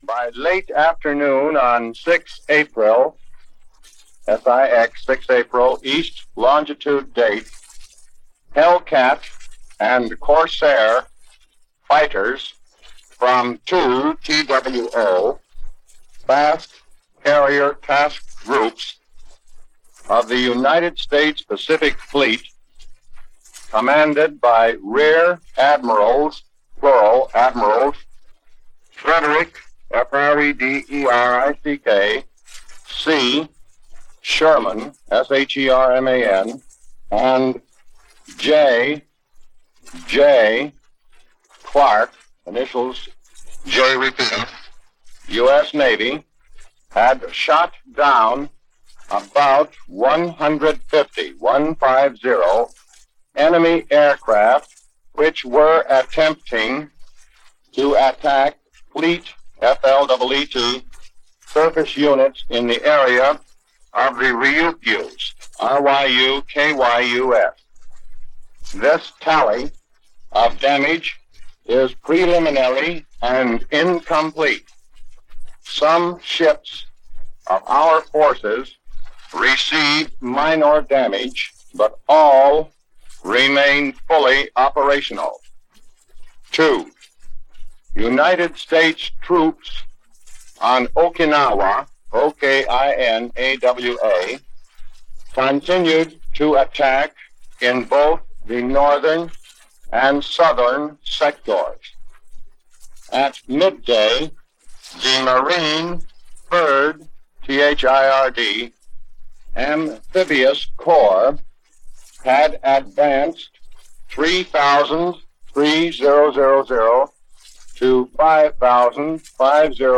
April 6, 1945 - Word from Okinawa - Operation Iceberg Underway - news for this day in World War 2 as reported by Mutual - Past Daily.
Okinawa-Reports-April-6-1945.mp3